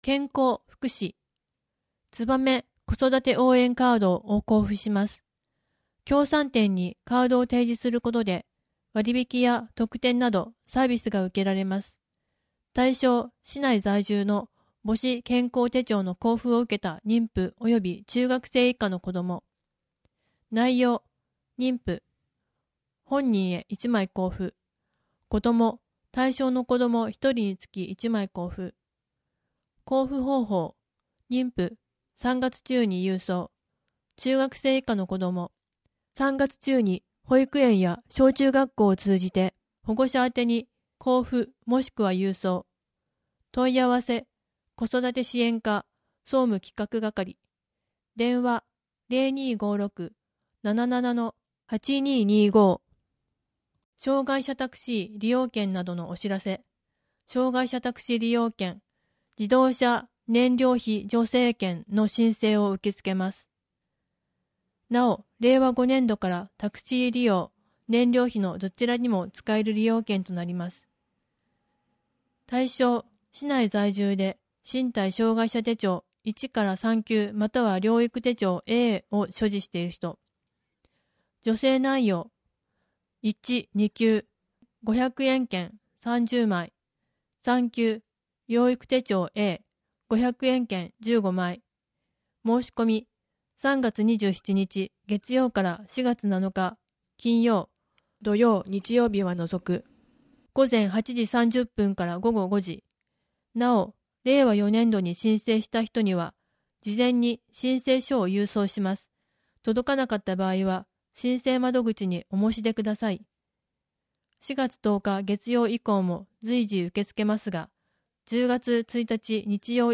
声の広報は、広報つばめを音読・録音したもので、デイジー版とMP3版があります。